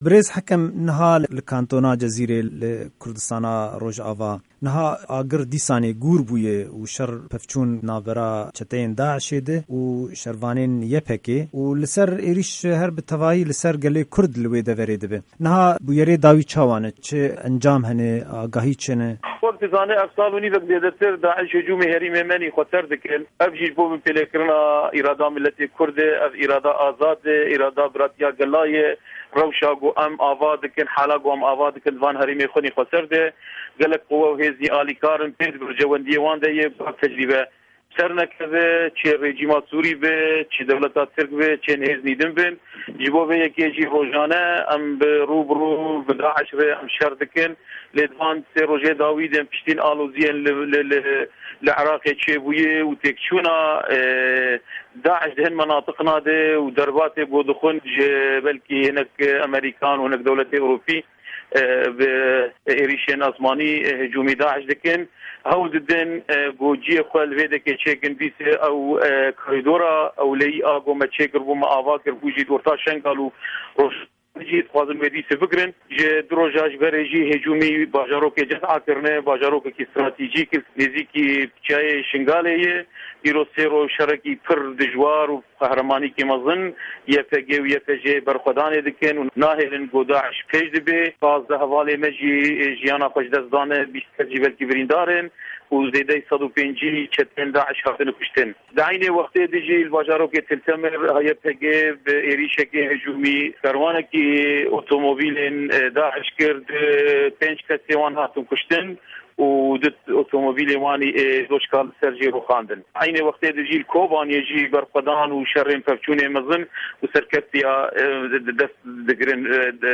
Hevpeyvina